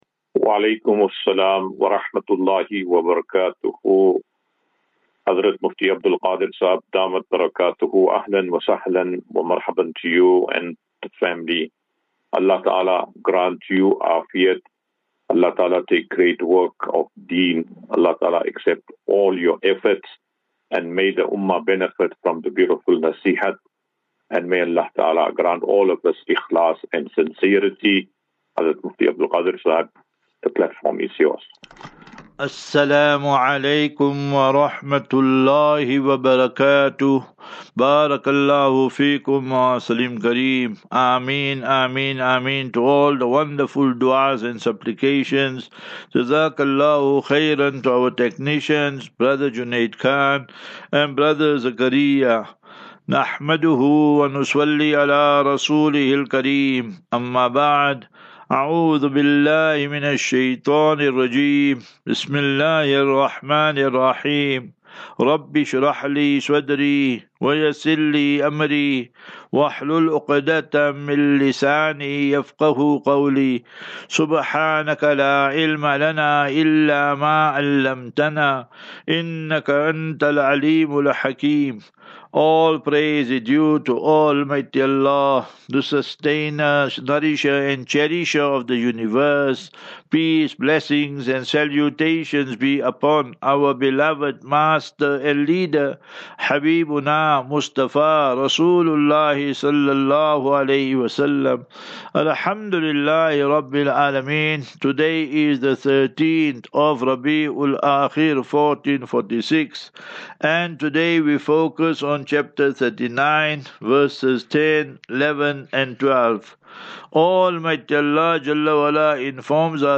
QnA.